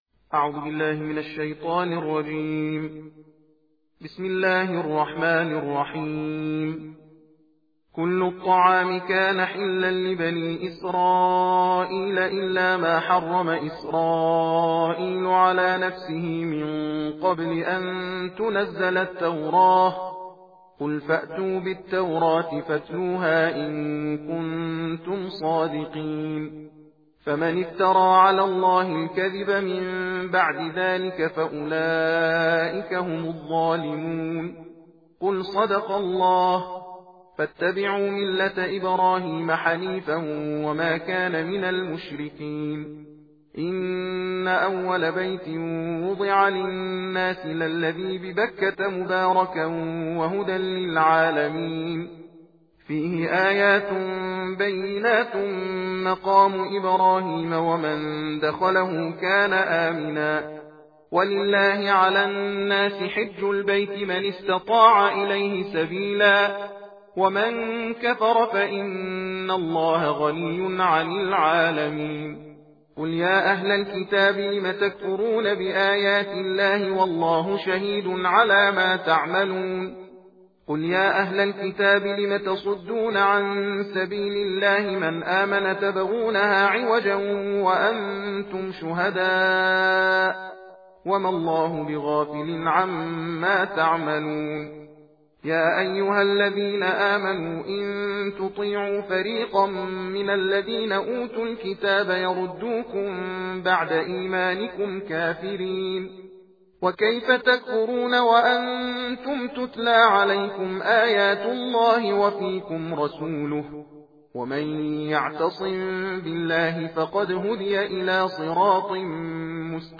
صوت/ تندخوانی جزء چهارم قرآن کریم